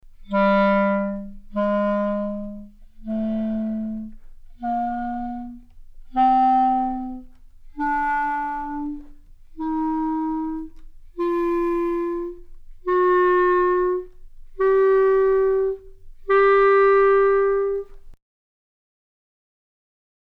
Example #92 presents a ten-note scale in the chalumeau register that exploits dark timbres.